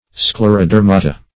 Search Result for " sclerodermata" : The Collaborative International Dictionary of English v.0.48: Sclerodermata \Scler`o*der"ma*ta\, n. pl.
sclerodermata.mp3